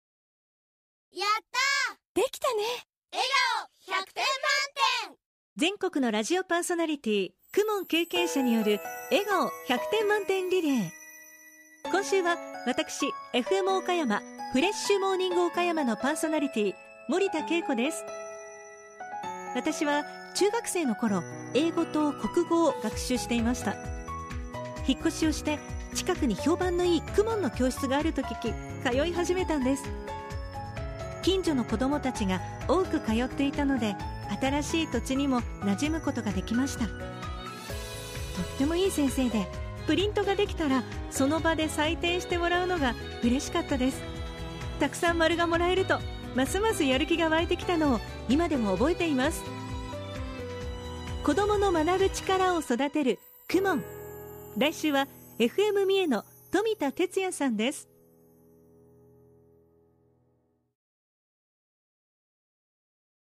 「子どもの頃、KUMONやってました！」 「今、子どもが通っています！」･･･という全国のパーソナリティのリアルな声をお届けします。